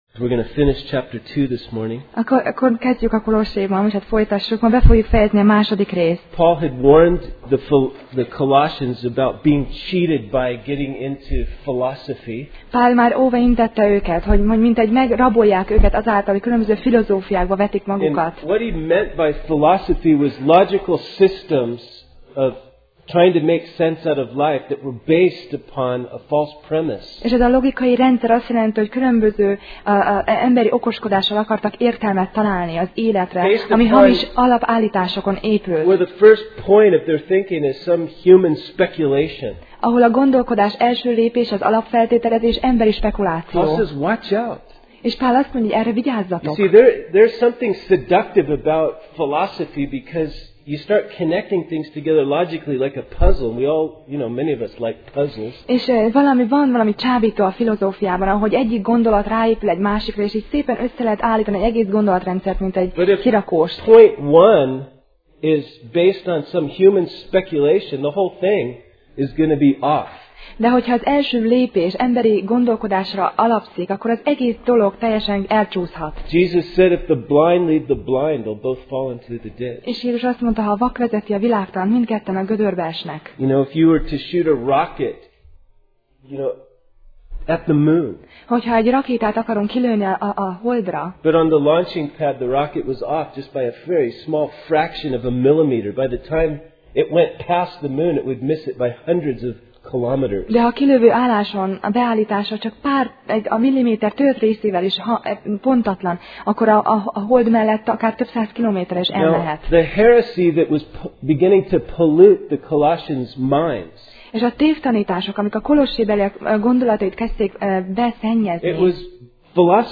Kolossé Passage: Kolossé (Colossians) 2:20-23 Alkalom: Vasárnap Reggel